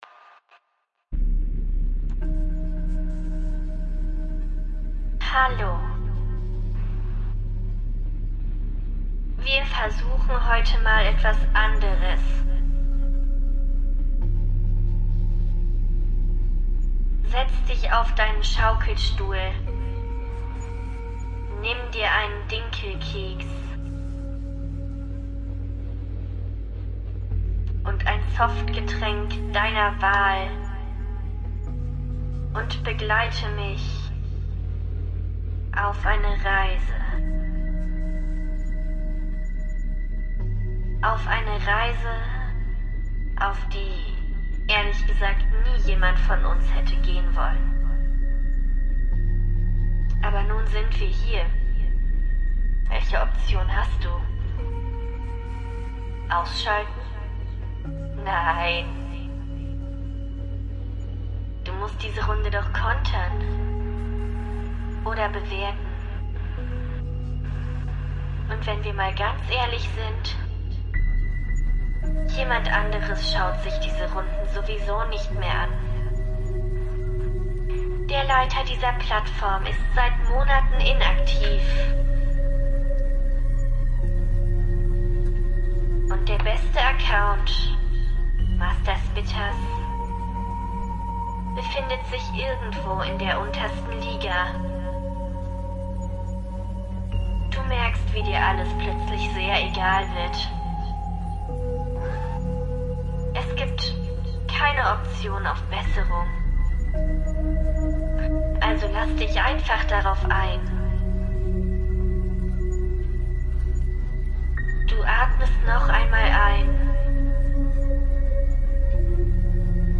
Richtiger ASMR shit, gibt save Leute die dazu einschlafen könnten wie ein Baby
Tipp: Kurze Runden sind meist besser Intro ist viel zu lang